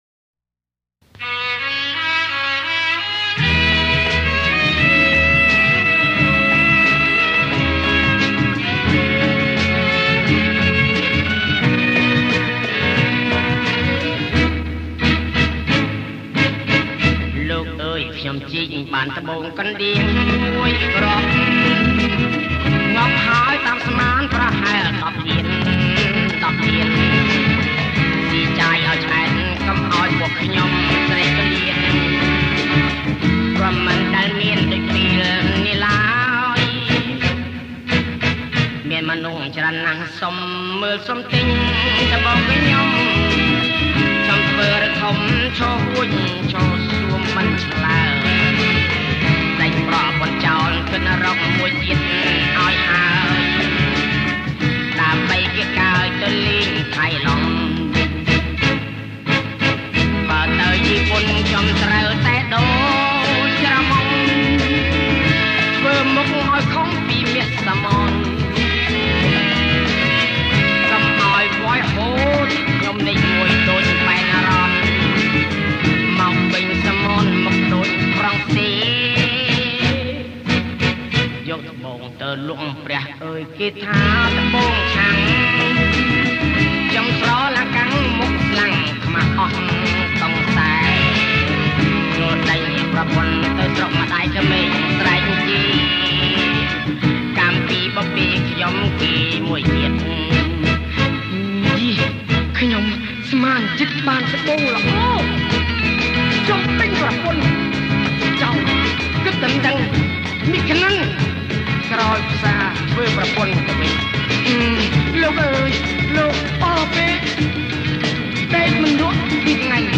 • ប្រគំជាចង្វាក់ Bossa nova